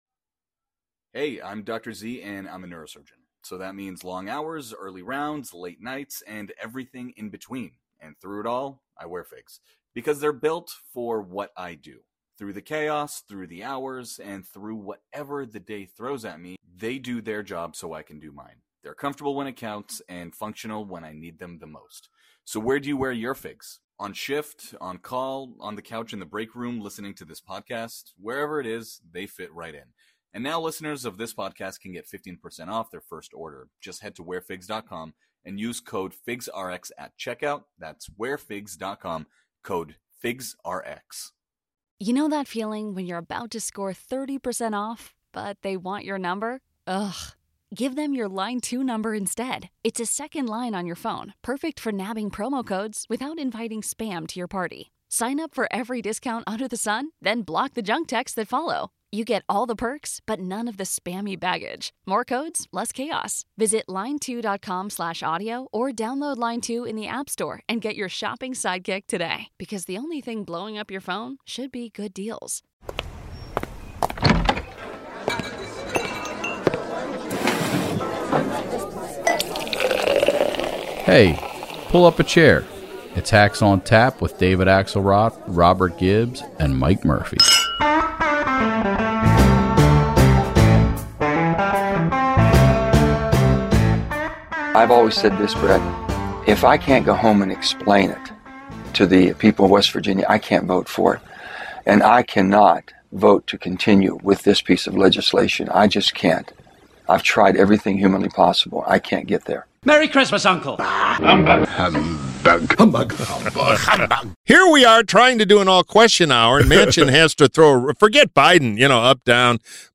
The Hacks discuss Manchin’s Build Back Better bombshell before taking questions from our esteemed listeners.